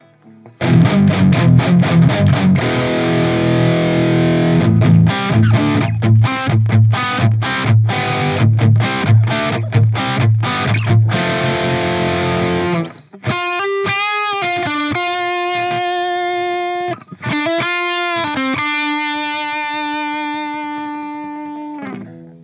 Tu je s profi impulzom Gitar Hack JJ Fred 45 (?)